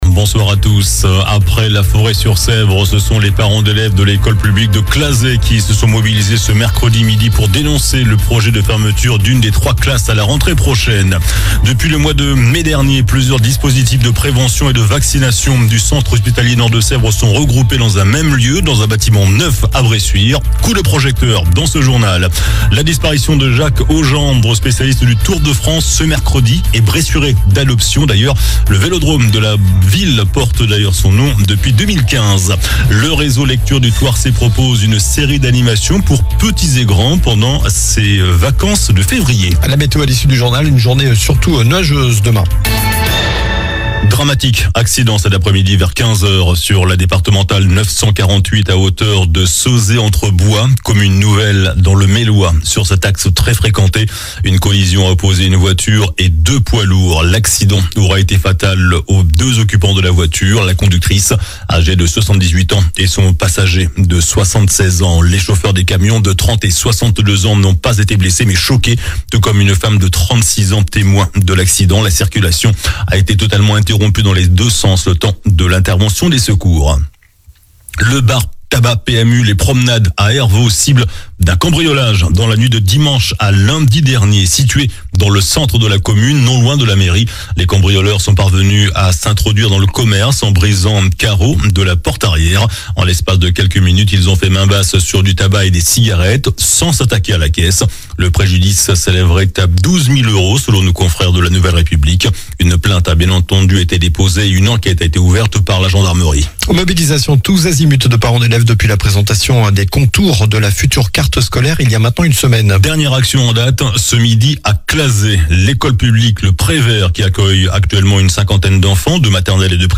JOURNAL DU MERCREDI 19 FEVRIER ( SOIR )